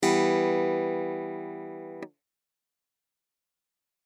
For this purpose I’m taking the standard preset in the Logic electric clav and I play a single E major chord.